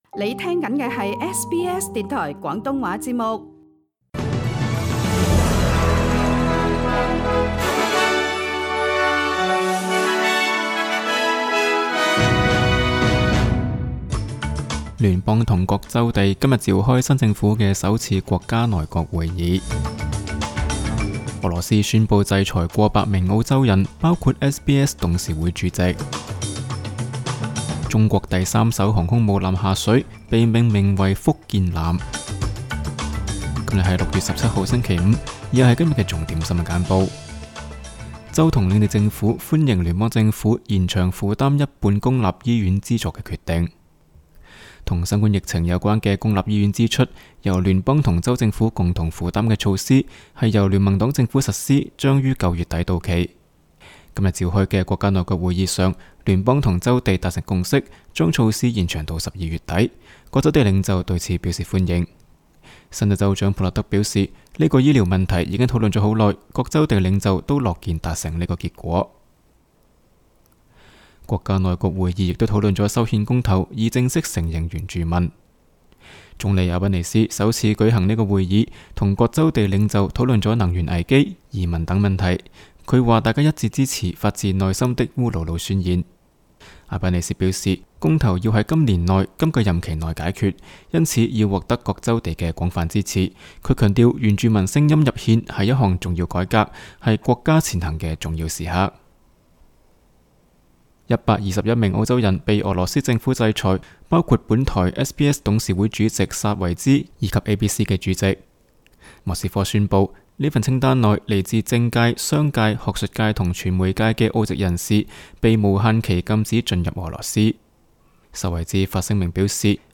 SBS 新闻简报（6月16日）
SBS 廣東話節目新聞簡報 Source: SBS Cantonese